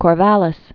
(kôr-vălĭs)